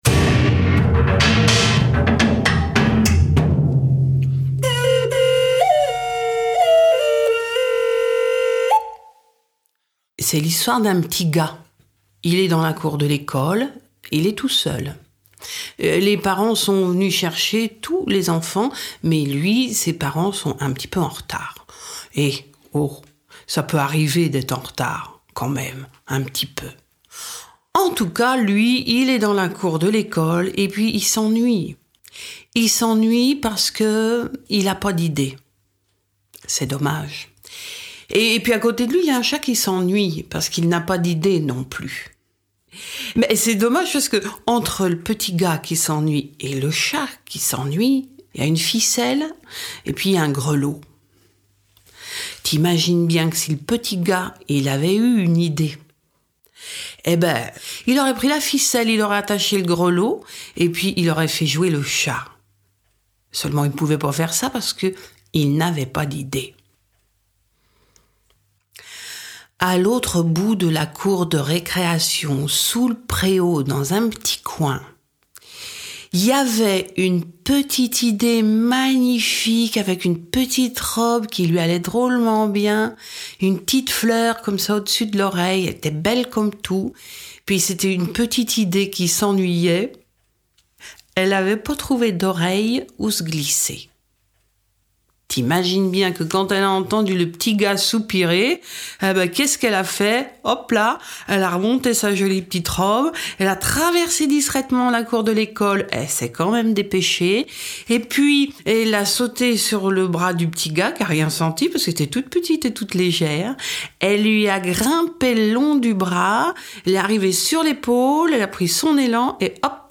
Contes croisés
On en fait même tout un festival de contes : Palabrages, le bien nommé.